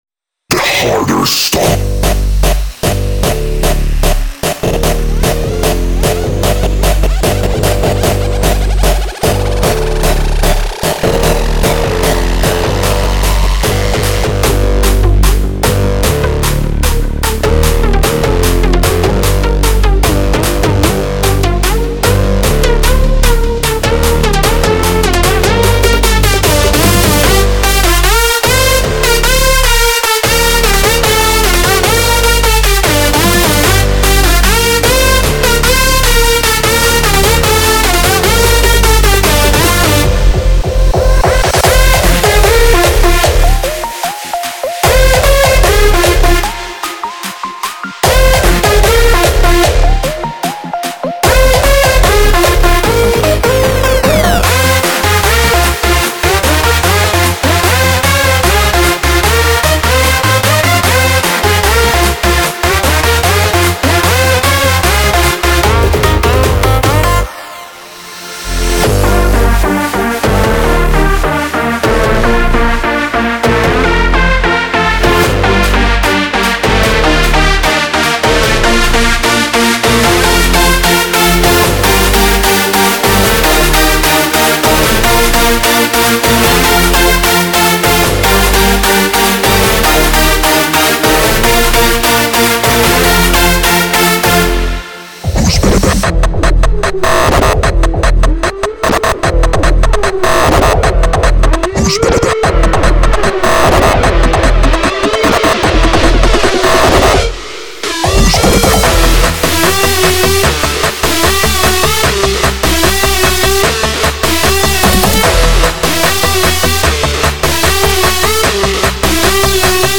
这种多类型的包充满了旋律，合成器，贝司，效果和循环，并包含WAV和MIDI格式。
标有音调和节奏– FX，循环，拍手，和弦和引线